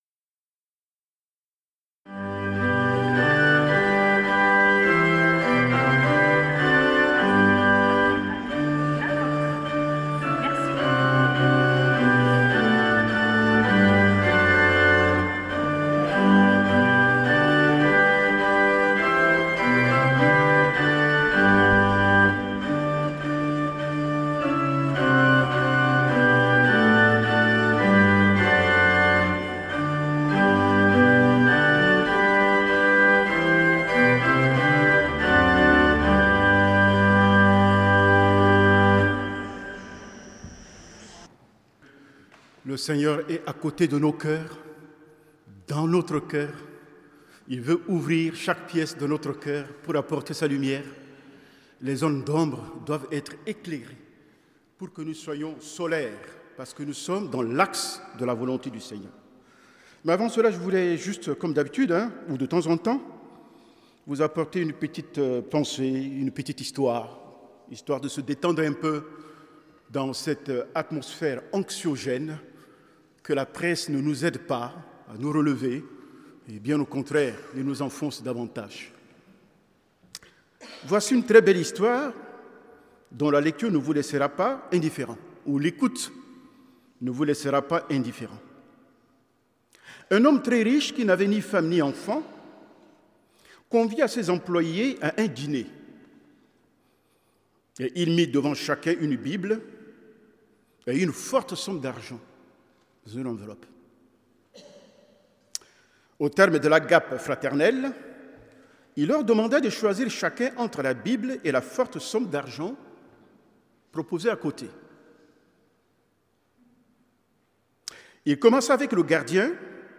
Prédication du 15 Mai 2022.